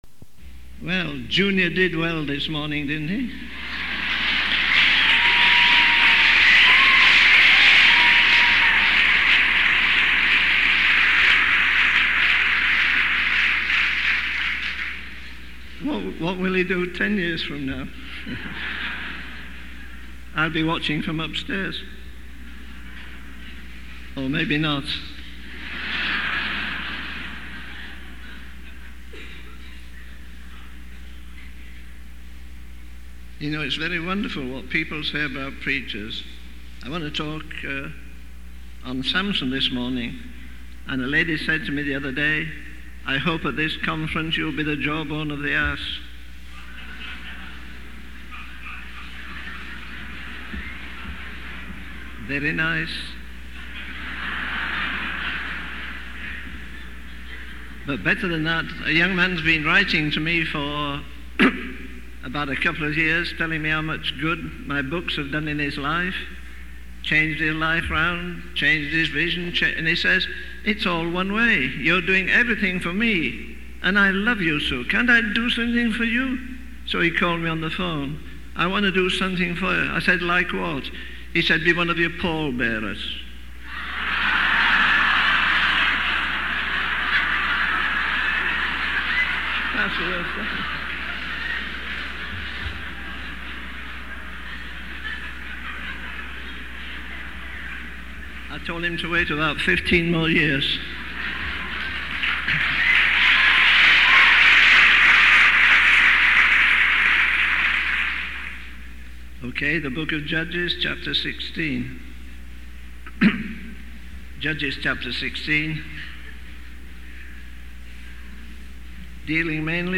In this sermon, the preacher emphasizes the importance of responding to God's call in the present moment.